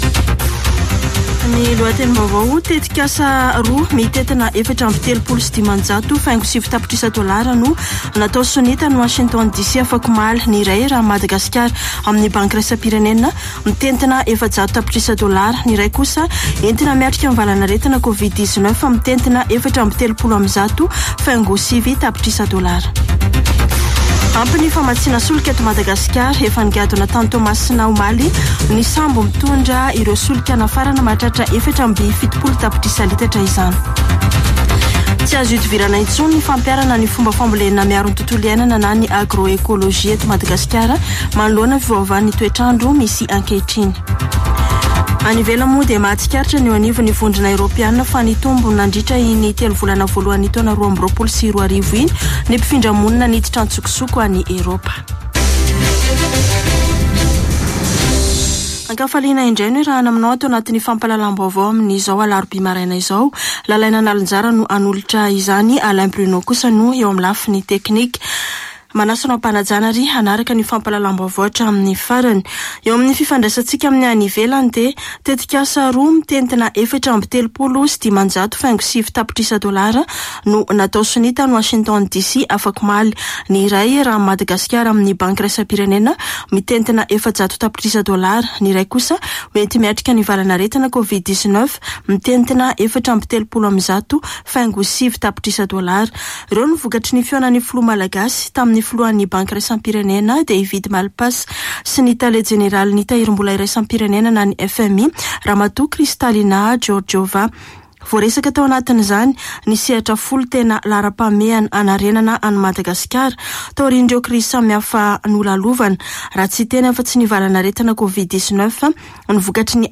[Vaovao maraina] Alarobia 20 aprily 2022